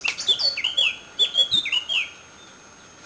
Oggi sono stato vicino ad un boschetto a Pereta (GR) e ho registrato vari canti uno dei quali è questo...
La mia è solo una sensazione quindi prendila con le molle, però mi ricorda la bigia grossa perché il verso (nonostante il suono sia distorto) è flautato, ma ripetitivo.
Capinera
Si sente solo una porzione di canto
Se trattasi di Capinera (Sylvia atricapilla),dovrebbe essere una forma dialettale.